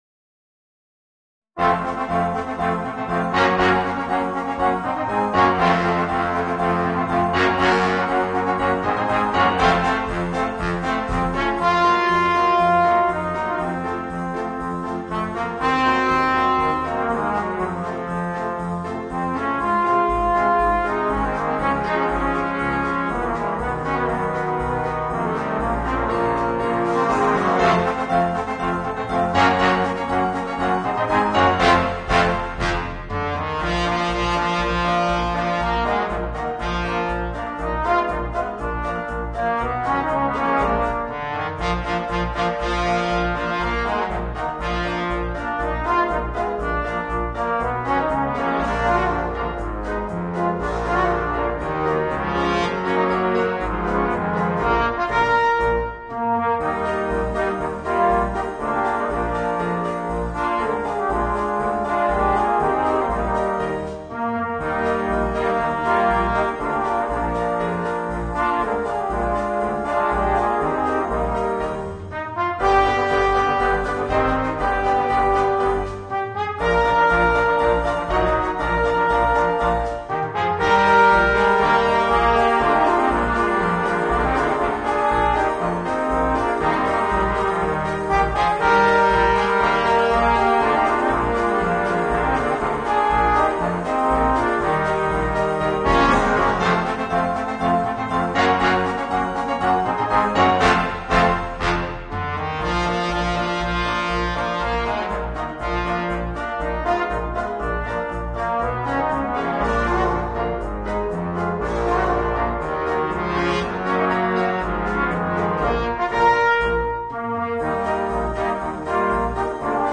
Voicing: 4 Trombones and Rhythm Section